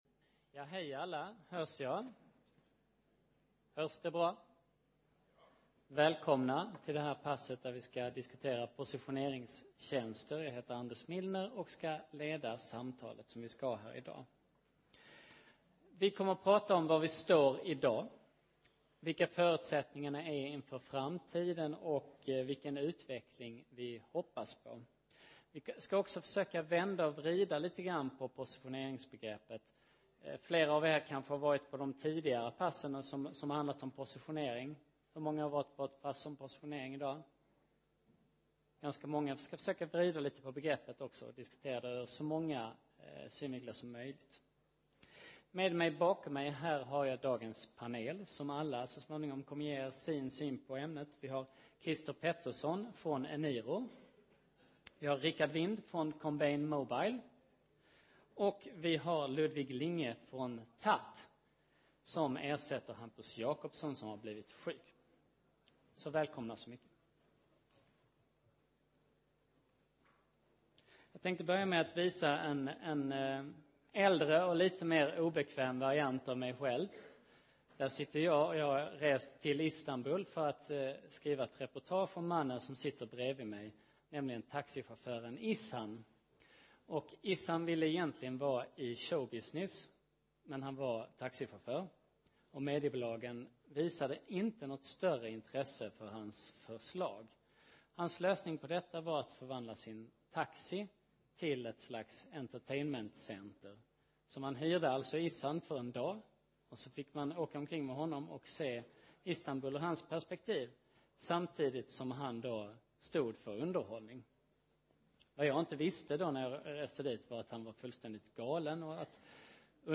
Kom och lyssna på en framtidsinriktad diskussion med några av branschens mest spännande aktörer.
Framtiden: Bättre än verkligheten (LIVESÄNDS) Plats: Kongresshall A Datum: 2010-10-26 Tid: 15:30-17:00 När datatillgången är säkrad och tekniken finns på plats, vad händer då?